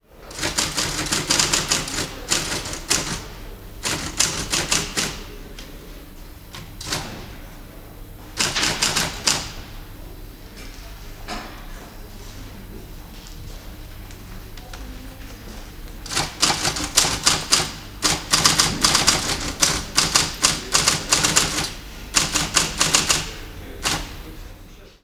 Звуки пишущей машинки
Живой звук: печатает на старой машинке в заводском цеху